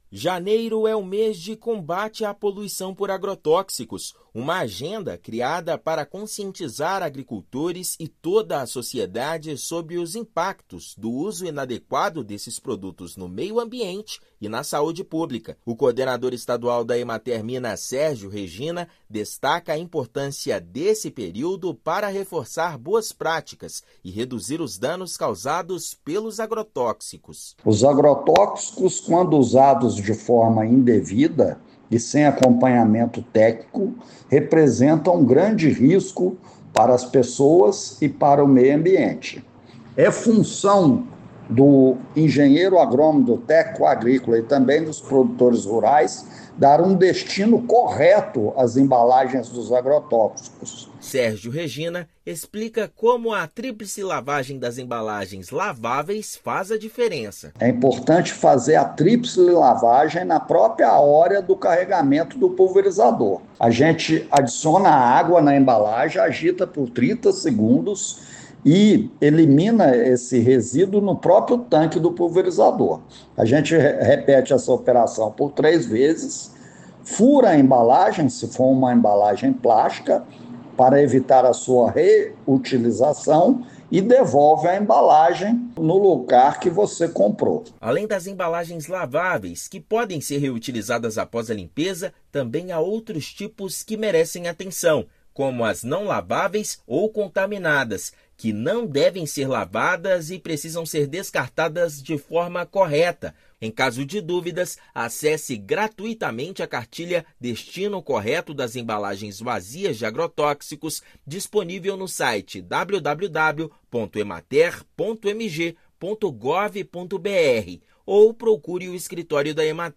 Práticas sustentáveis são essenciais para proteger o meio ambiente e a saúde dos produtores, como a tríplice lavagem de embalagens e o descarte correto em postos autorizados. Ouça matéria de rádio.